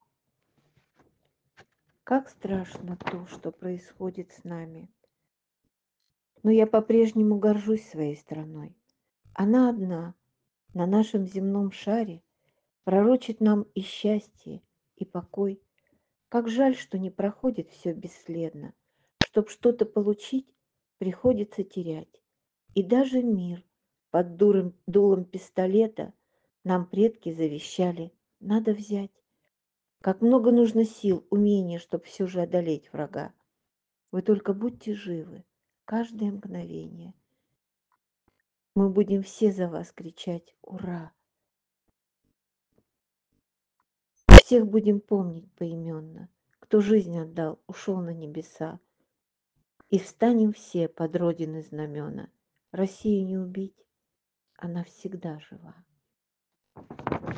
Незрячие и слабовидящие читатели пишут «звуковые» письма в поддержку героев СВО.